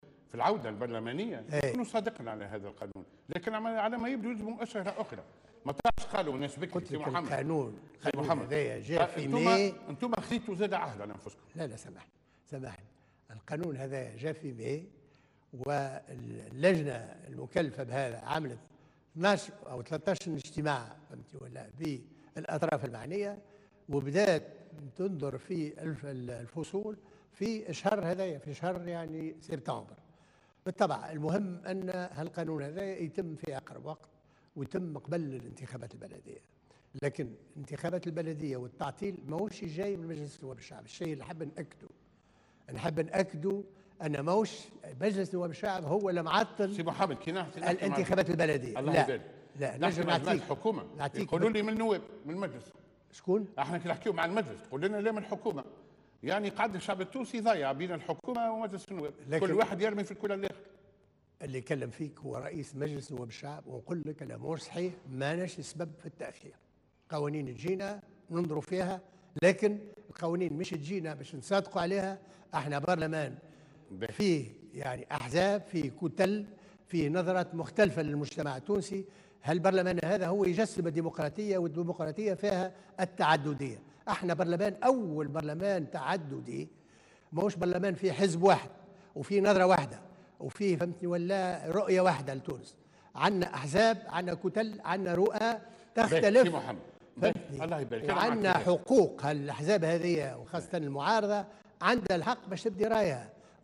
وأضاف في حوار له اليوم على القناة الوطنية الأولى أن المجلس ليس سببا في تعطيل المصادقة على قانون الجماعات المحلية وليس مسؤولا عن تأخير الانتخابات.